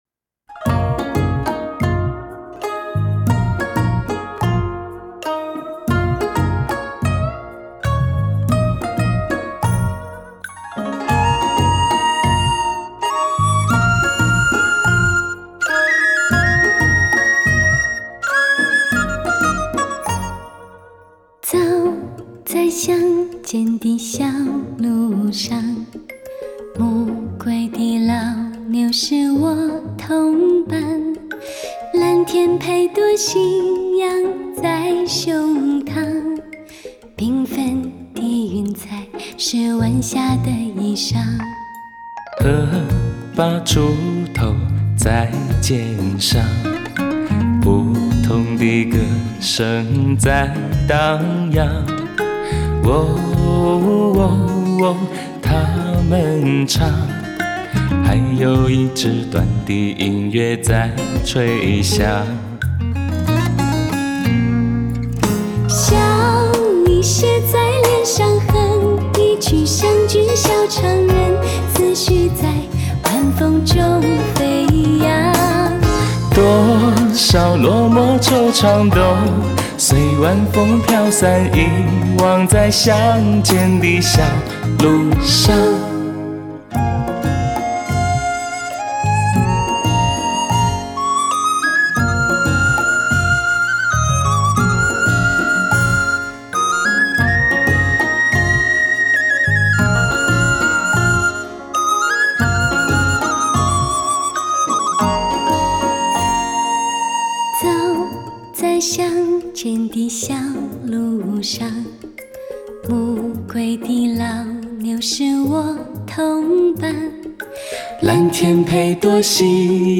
男女二重唱深情演绎经典岁月